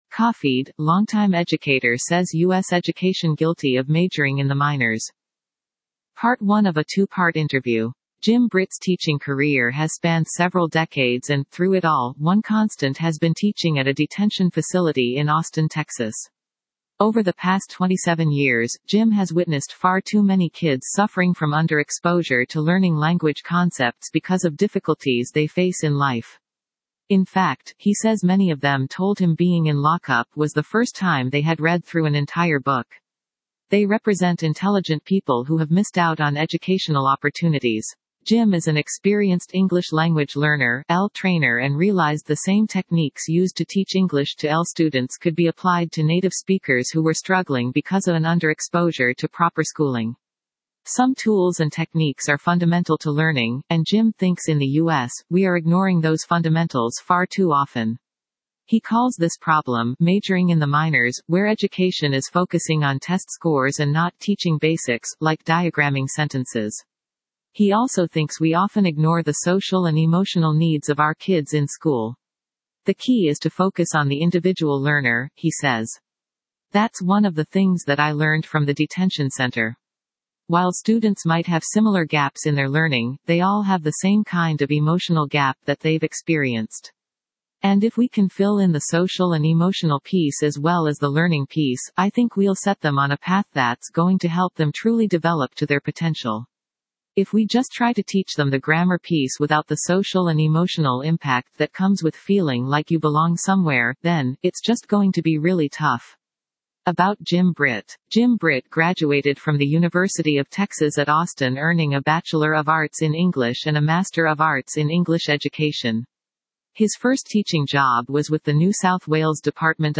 Part One of a Two-Part Interview